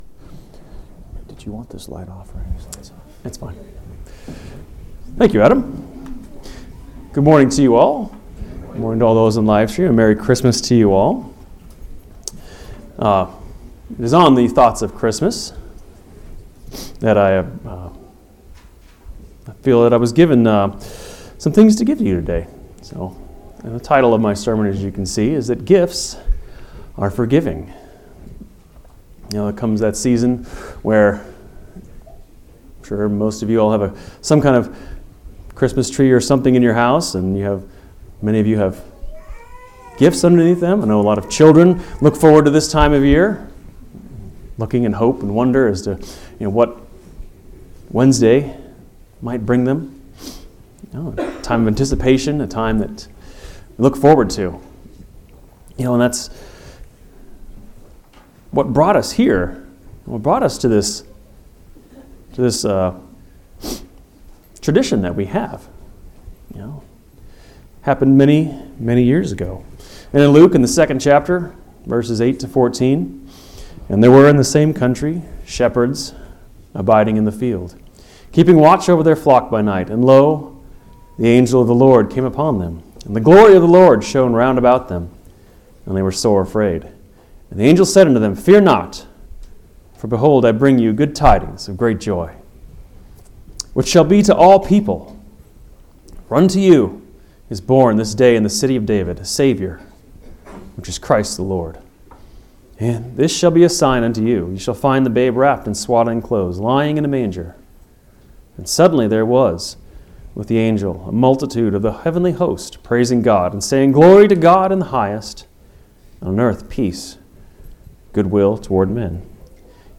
12/22/2019 Location: Phoenix Local Event